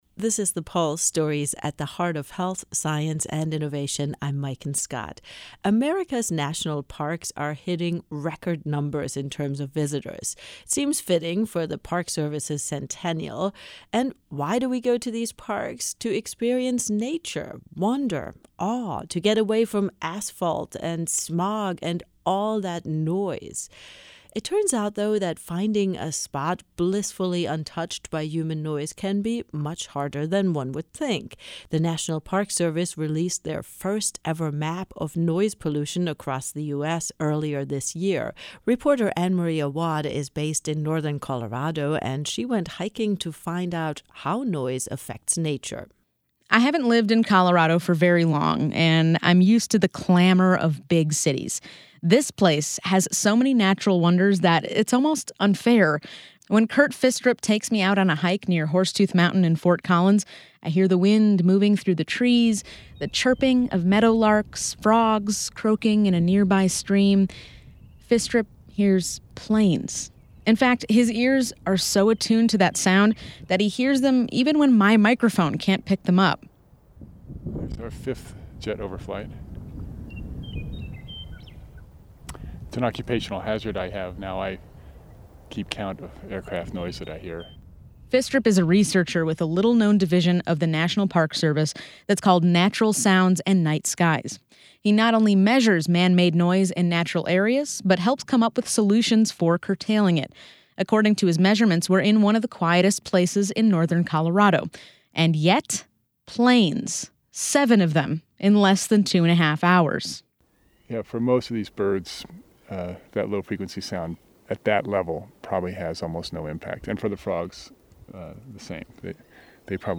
Though they were several feet away from us, the birdsong projected so far, it sounded like the birds were right behind us.
Over a hill, we could hear the loud croaking of a chorus of frogs.
Crossing the creaky footbridge over the Poudre River, the drone of the adjacent water treatment facility faded into the distance. The beeping from nearby construction vehicles gradually became faint, but proved to be inescapable.